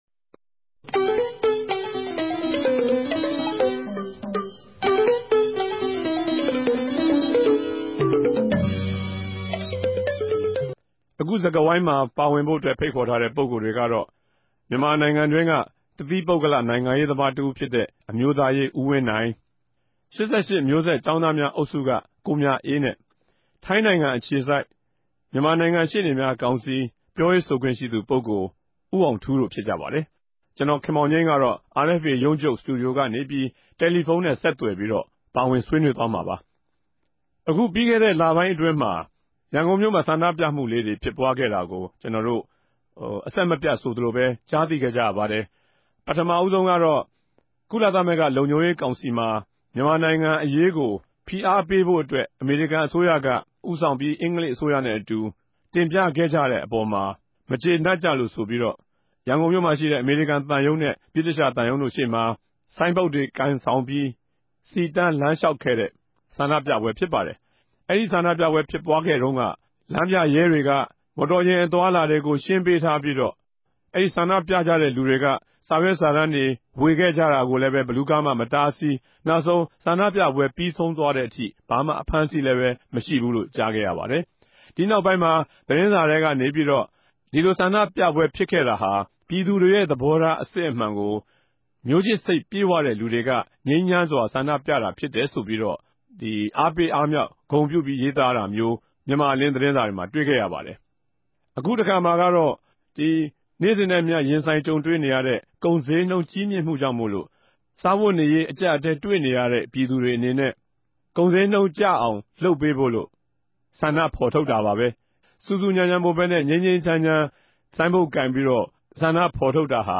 ႟ုံးခဵြပ် စတူဒီယိုကနေ တယ်လီဖုန်းနဲႛ ဆက်သြယ်္ဘပီး
တနဂဿေိံြ ဆြေးေိံြးပြဲစကားဝိုင်း